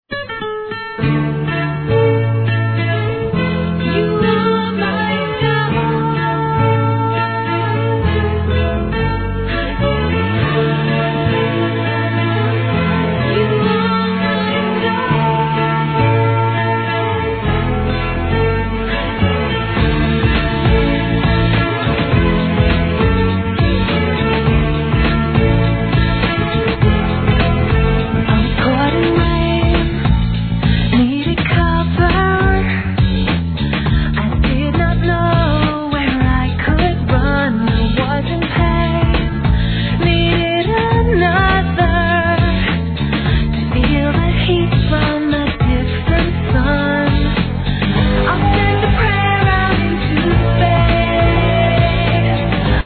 HIP HOP/R&B
「激キャッチー＆鬼ポップ」